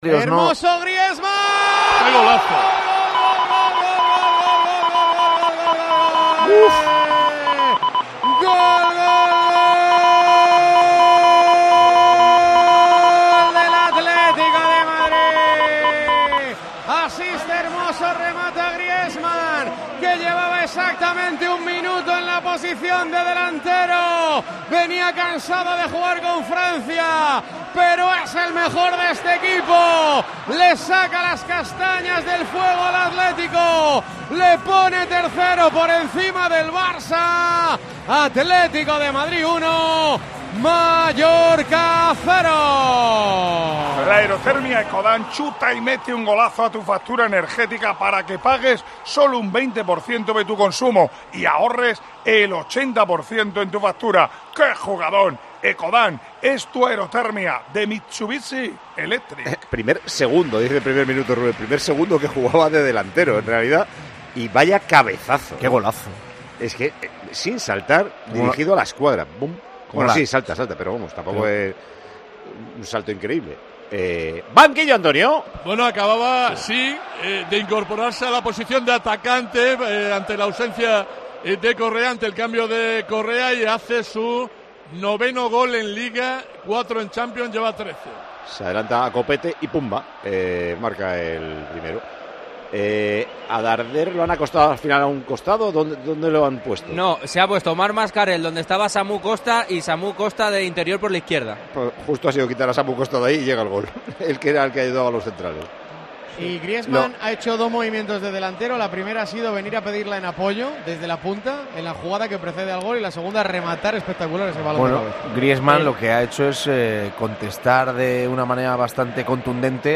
ASÍ TE HEMOS CONTADO EN TIEMPO DE JUEGO LA VICTORIA DEL ATLÉTICO DE MADRID